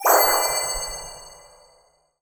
magic_light_bubble_02.wav